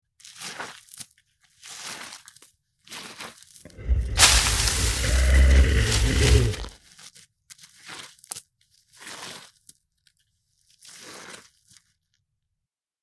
Download Free Bear Sound Effects | Gfx Sounds
This set captures the essence of Bear life, featuring authentic audio of Roars, Snarls, Growls, and Bear Movements!
Bear-movement-and-growls.mp3